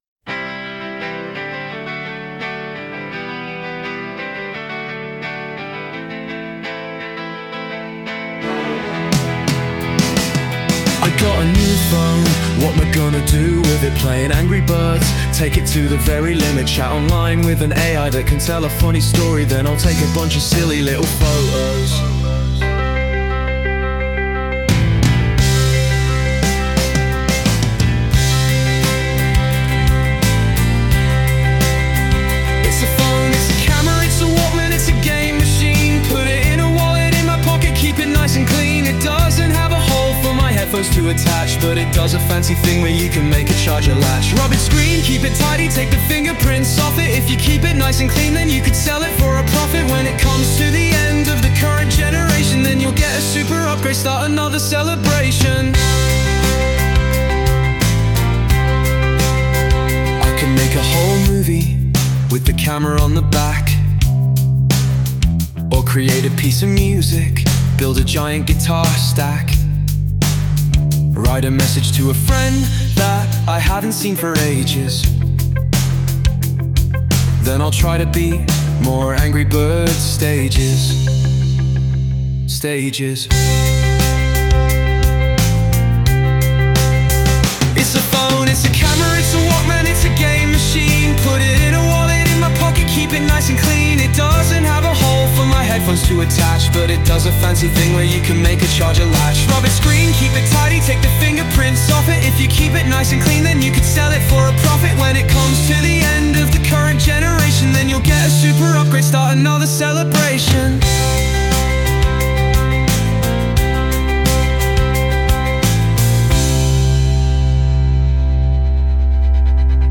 Sung by Suno
On_My_New_Phone_(Cover)_mp3.mp3